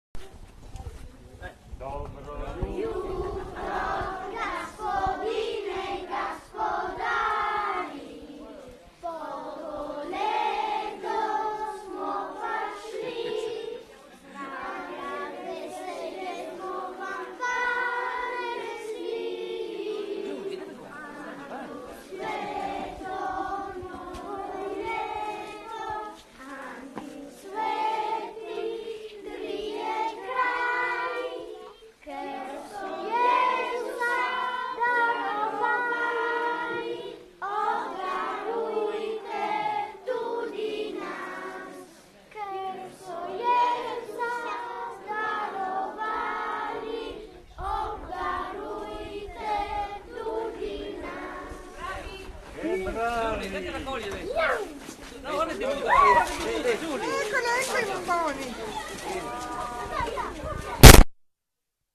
Oggi, nelle Valli del Natisone, si svolge solo a Cicigolis (Pulfero), dove l’ultimo giorno dell’anno la mattina la koleda la fanno i bambini che ricevono noci, noccioline, castagne e caramelle in cambio del canto beneaugurante, mentre la sera gli uomini del paese portano di casa in casa la stella dei Magi.